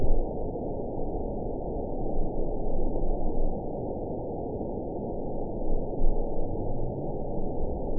event 919853 date 01/26/24 time 01:23:35 GMT (1 year, 10 months ago) score 7.67 location TSS-AB07 detected by nrw target species NRW annotations +NRW Spectrogram: Frequency (kHz) vs. Time (s) audio not available .wav